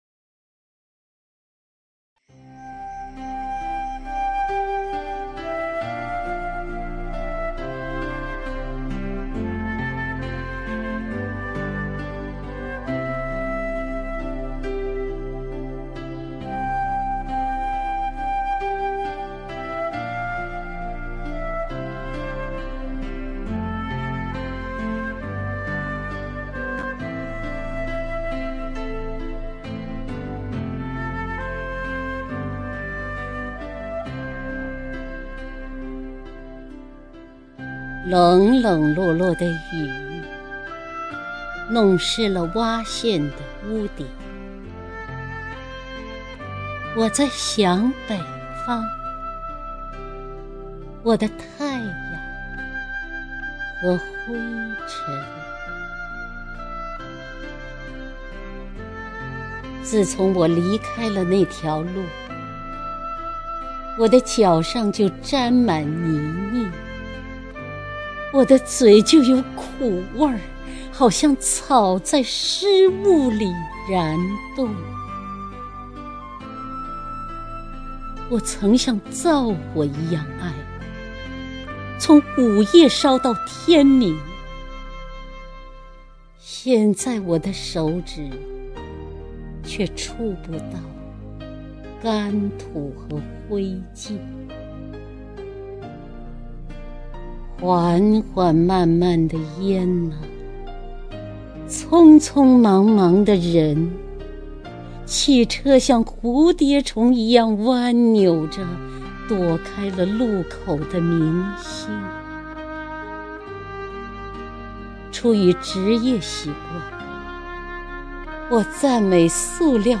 姚锡娟朗诵：《异地》(顾城)